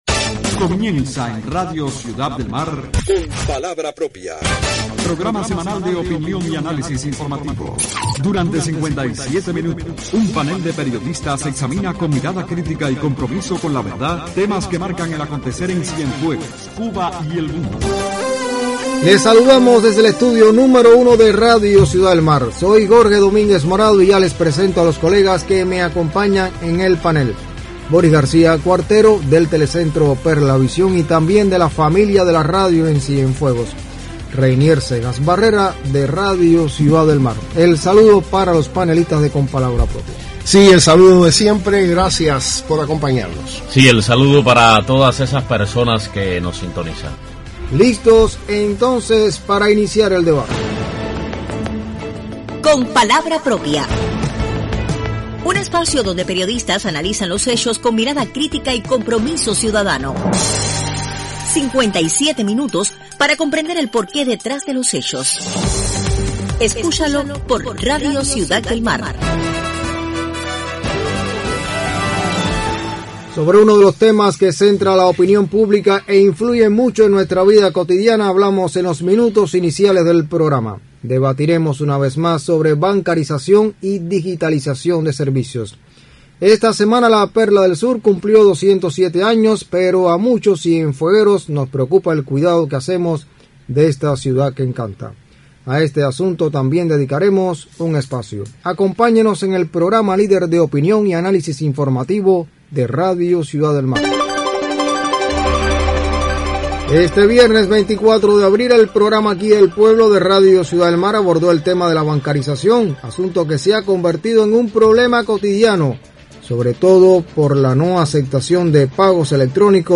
Los tropiezos que atentan contra la marcha del proceso de bancarización centran el debate en la emisión del 25 de abril del programa Con palabra propia, por Radio Ciudad del Mar.